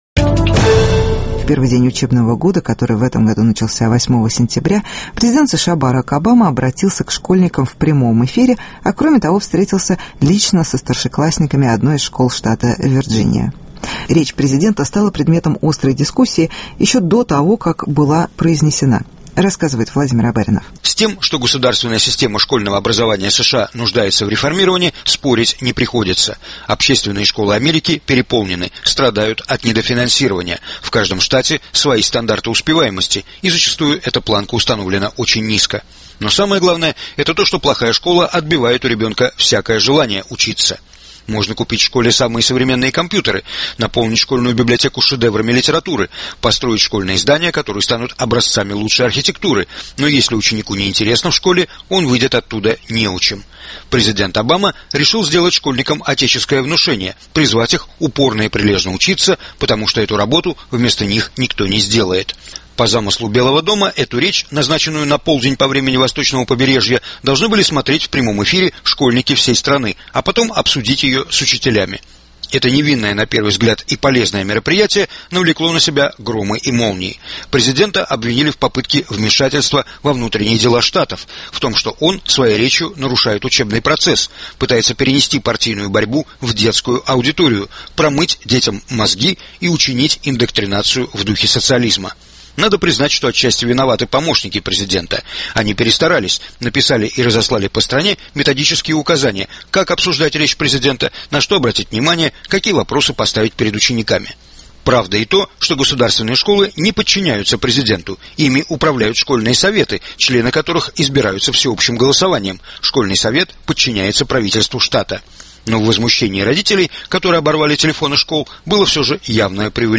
Школьная речь президента Обамы